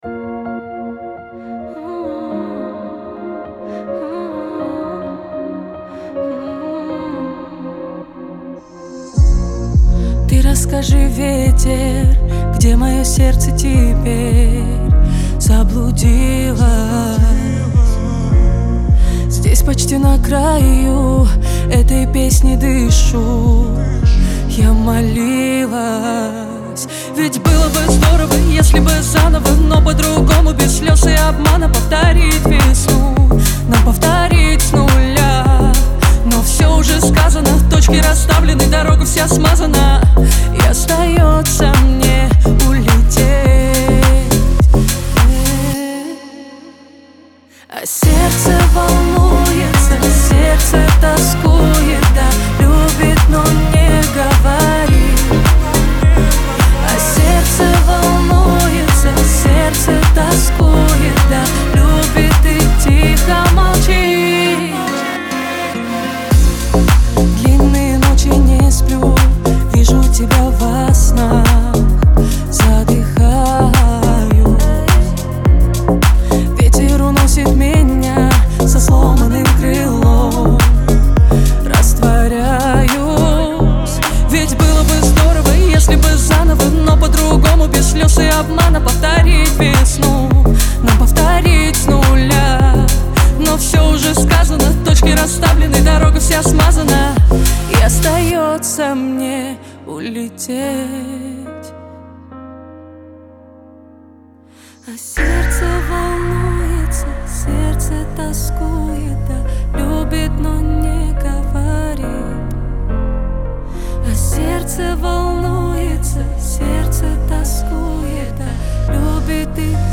pop
грусть